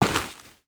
Light  Dirt footsteps 4.wav